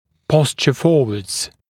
[‘pɔsʧə ‘fɔːwədz][‘посчэ ‘фо:уэдз]сместить в переднее положение (о нижней челюсти)